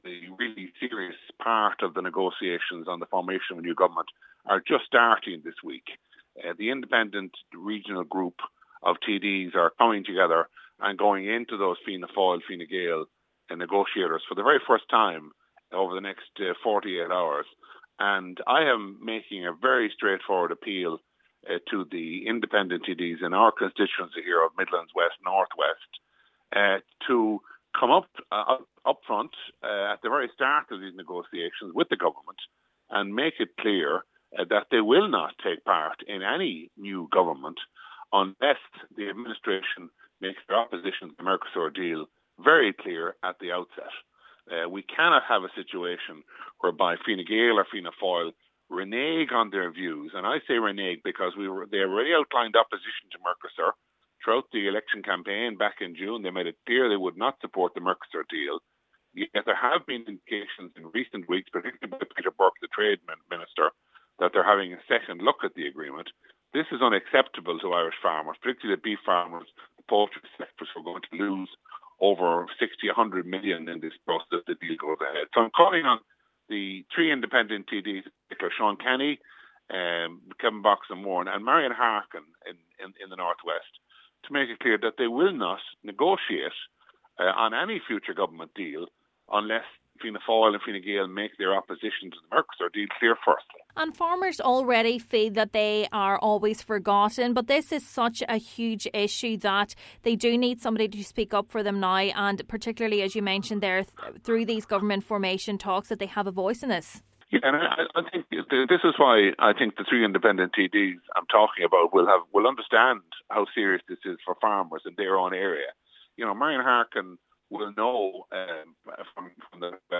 Mr Mullooly says they need to stand up for farmers during this week’s talks: